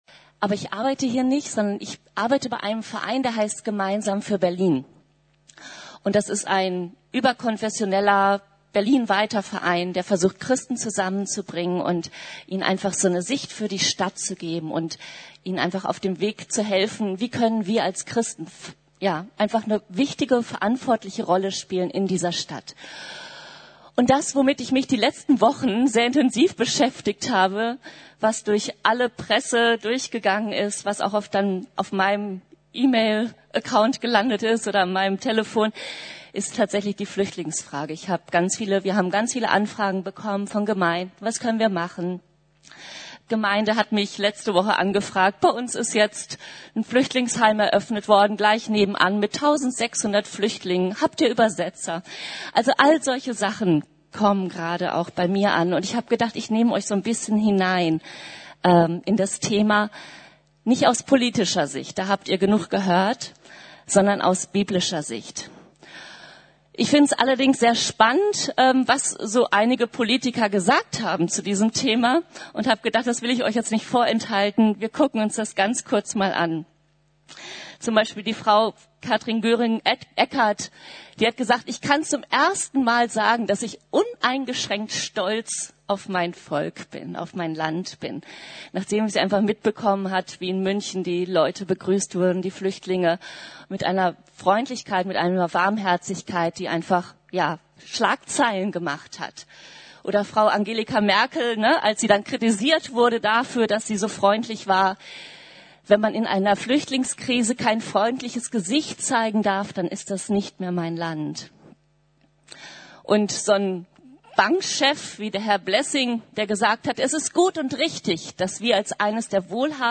Ich war Fremder und ihr habt mich aufgenommen! ~ Predigten der LUKAS GEMEINDE Podcast